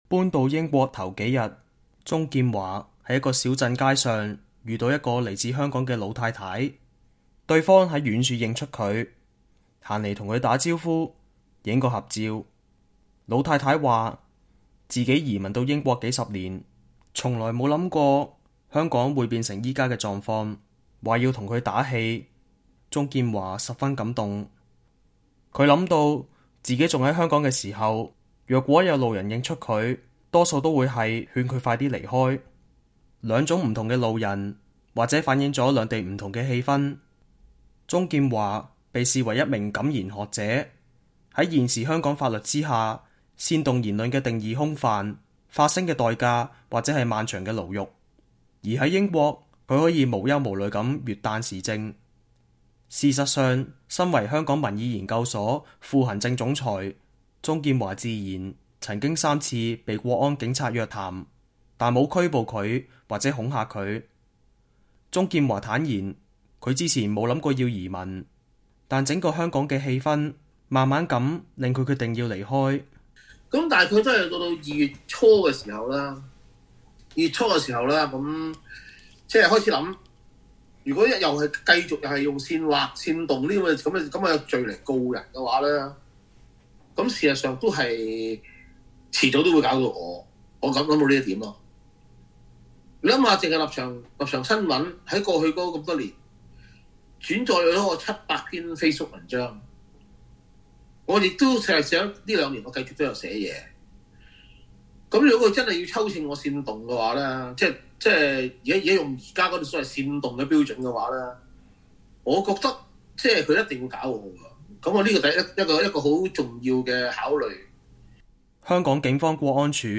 美國之音專訪鍾劍華